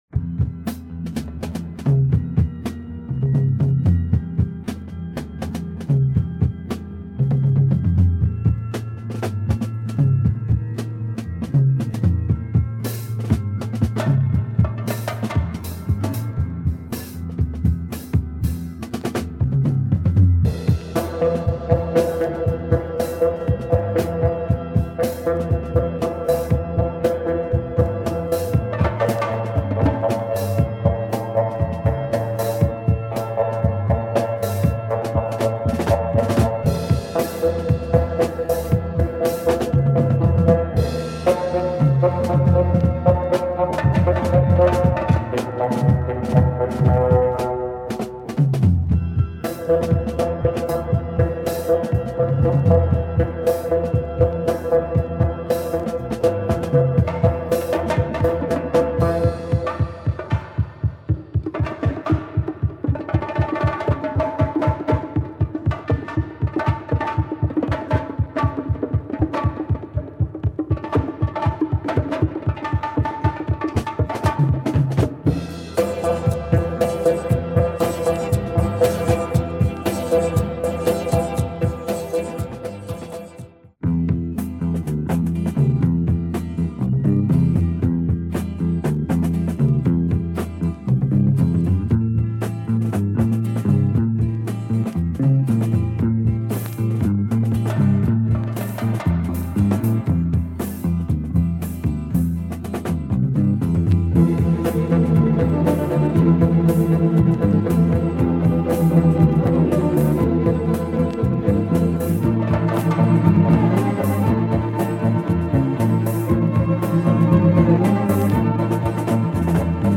Wanna hear some heavy heavy drums ?
Storming, haunting sounds and killer breaks !